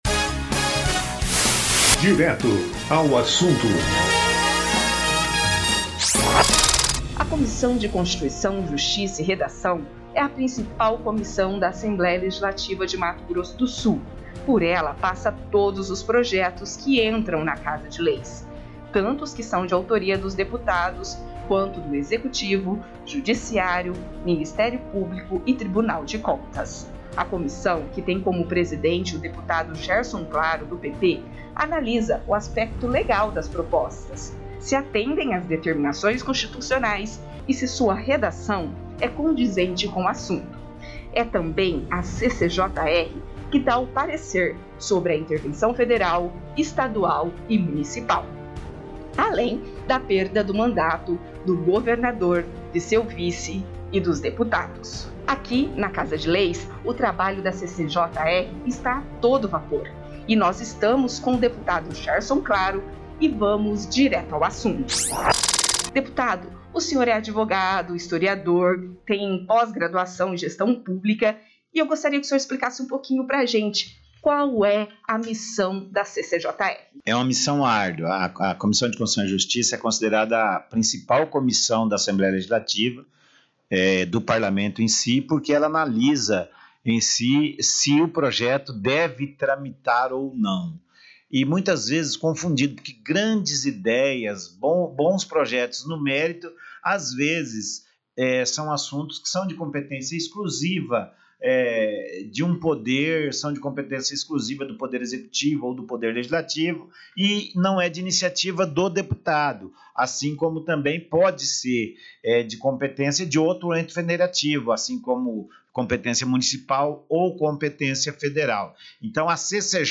O programa Direto ao Assunto da Rádio ALEMS, que vai ao ar nesta sexta-feira (21), conta com a participação do presidente da Comissão de Constituição, Justiça e Redação (CCJR), Gerson Claro (PP). Durante a entrevista foram abordados assuntos relacionados ao trabalho desenvolvido pela comissão, que é considerada uma das mais importantes da Assembleia Legislativa de Mato Grosso do Sul.